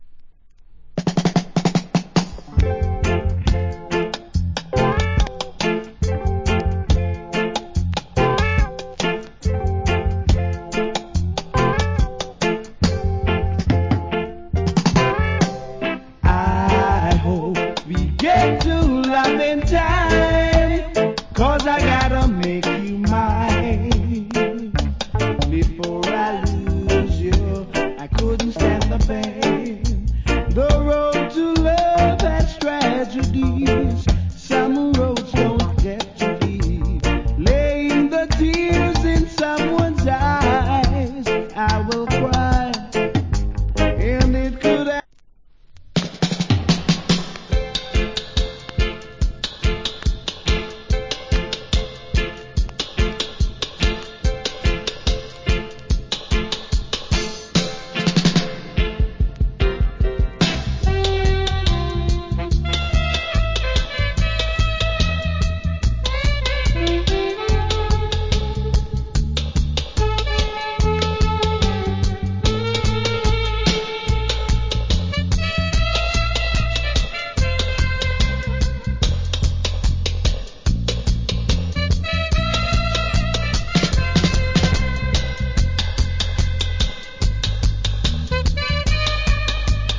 Great Vocal.